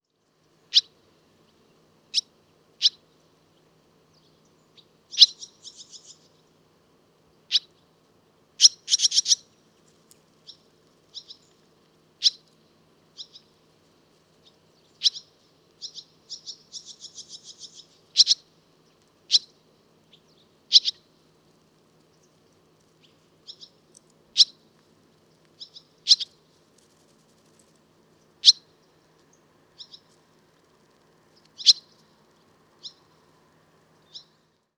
Canto